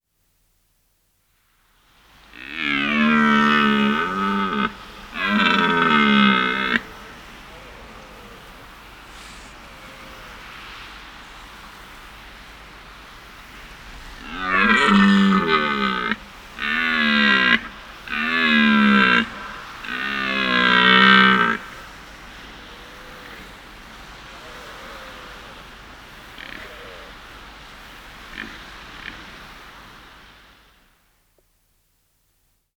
Rothirsch Geräusche
• In der Brunftzeit stoßen Hirsche laute Röhrschreie aus, um Rivalen zu beeindrucken.
Rothirsch-Geraeusche-Wildtiere-in-Europa.wav